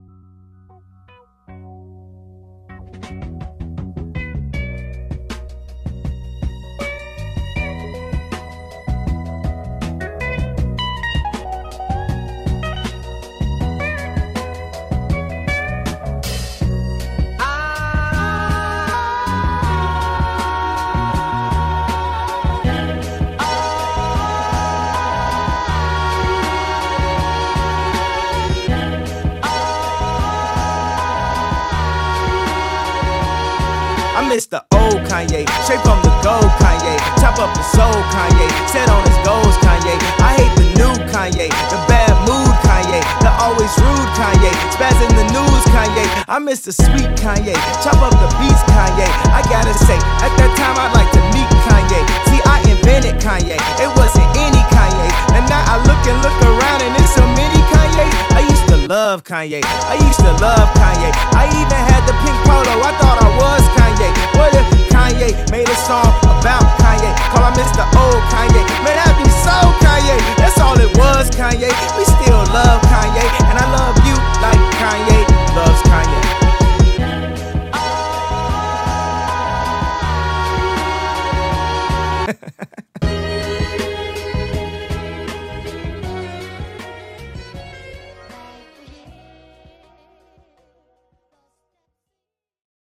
Song Remix